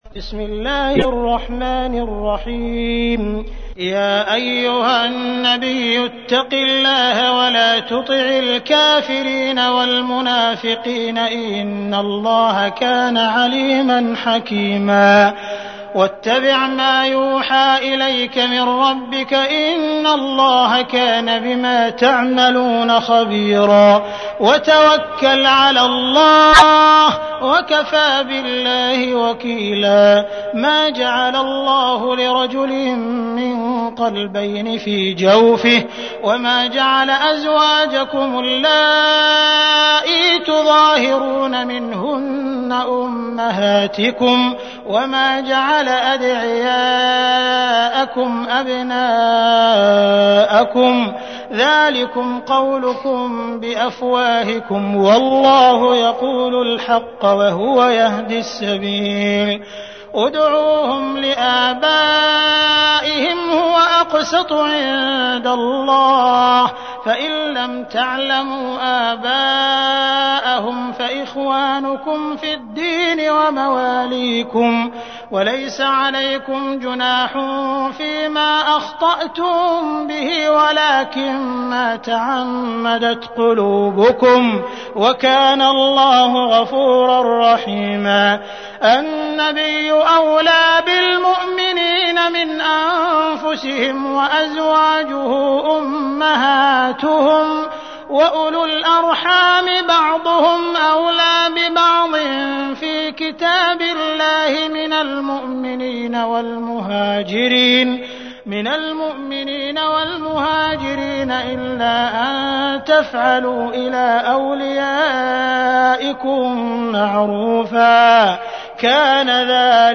تحميل : 33. سورة الأحزاب / القارئ عبد الرحمن السديس / القرآن الكريم / موقع يا حسين